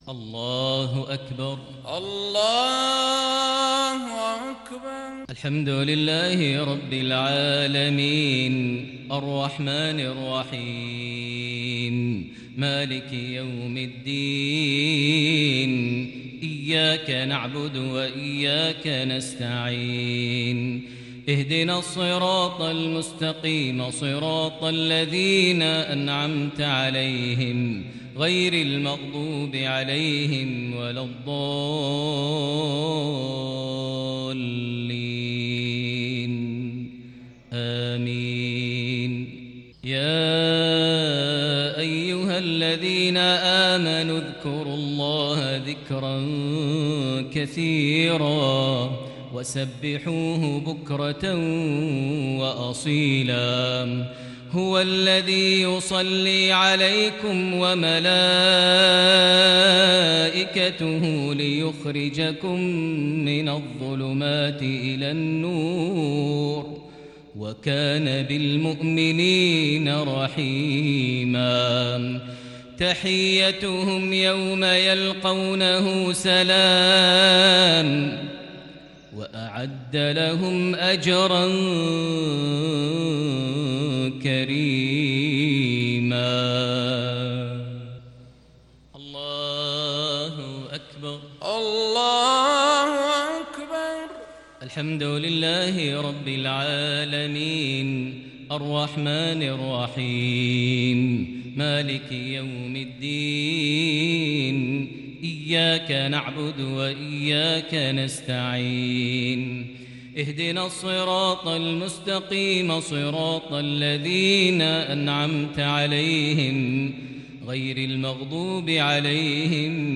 صلاة المغرب للشيخ ماهر المعيقلي 20 صفر 1442 هـ
تِلَاوَات الْحَرَمَيْن .